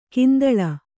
♪ kindaḷa